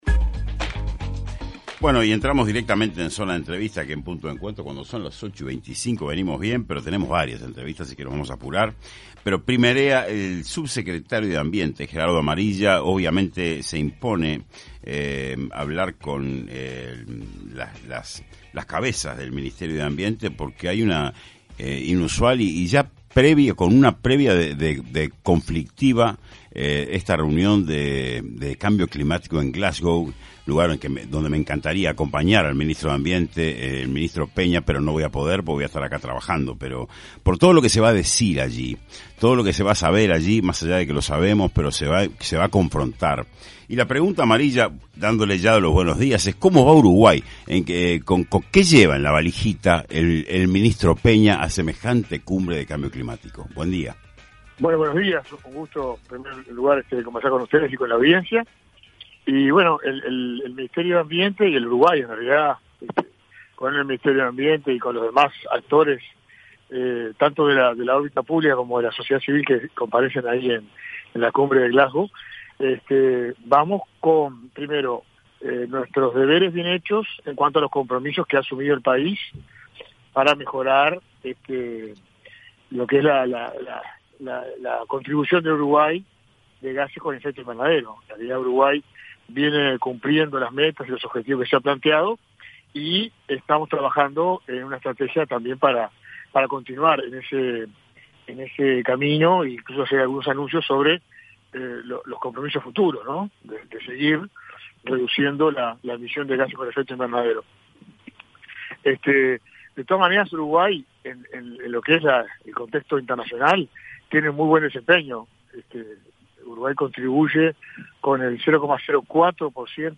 En entrevista en Punto de Encuentro el subsecretario de ambiente, Gerardo Amarilla, adelantó que Uruguay y Brasil en dicha cumbre anunciarán el punto de partida para iniciar una mejora en la cuenca de la laguna Merín.